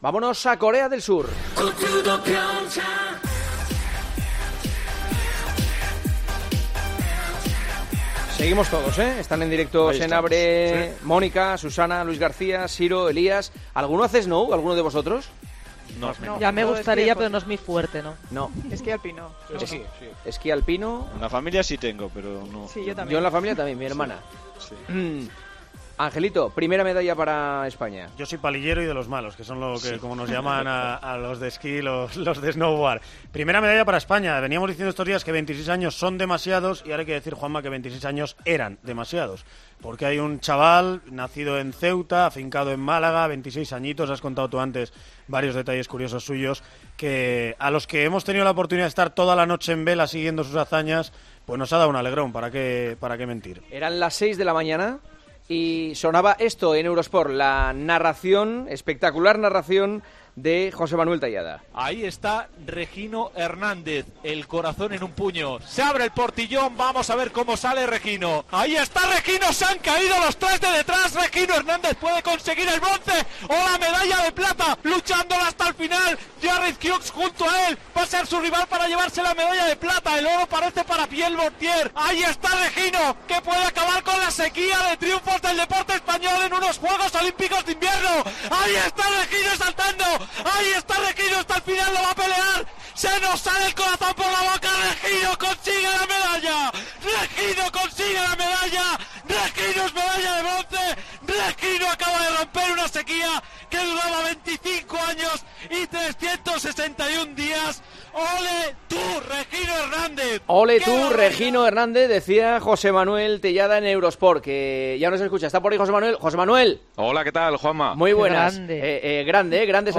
AUDIO: Juanma Castaño charló con el medallista de bronce de los JJOO de Invierno, un día después de subir al podio de Pyeongchang.